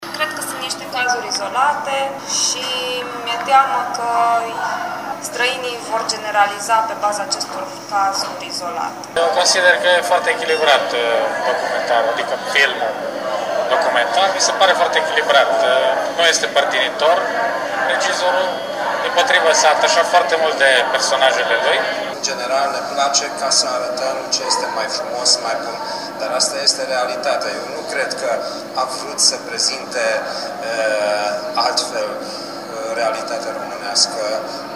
vox-uri.mp3